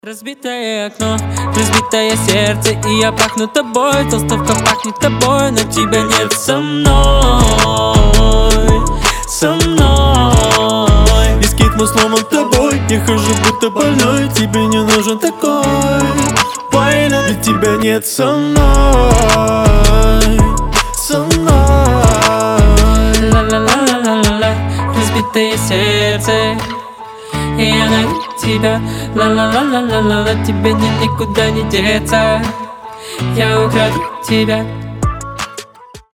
• Качество: 320, Stereo
гитара
мужской голос
лирика
дуэт